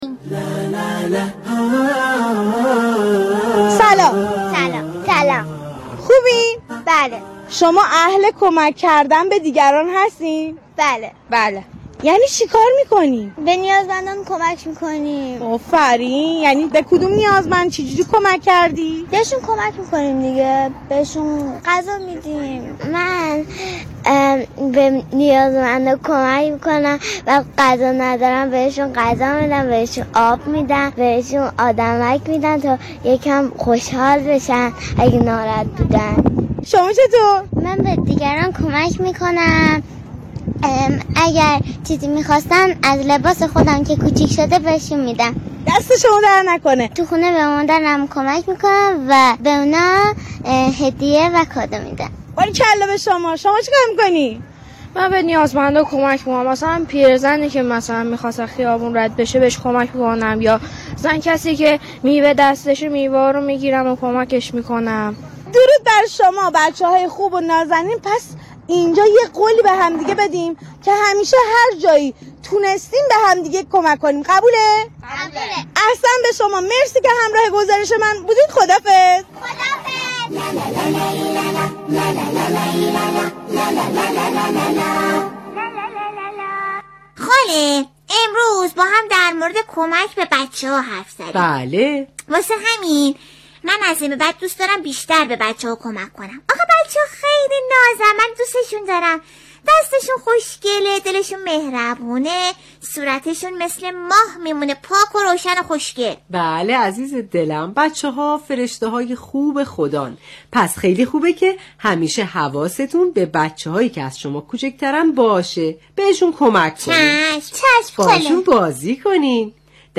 «بر آستان جانان» عنوان ویژه‌برنامه افطار رادیو قرآن است که به صورت زنده به روی آنتن این شبکه می‌رود.